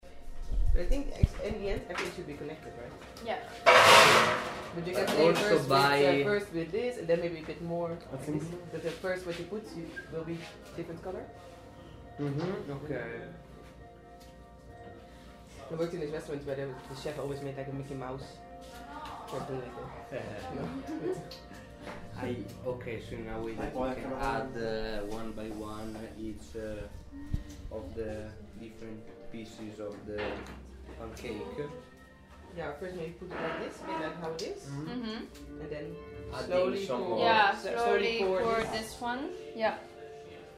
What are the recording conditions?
part2-5_group2 moved the recorder to the other side.mp3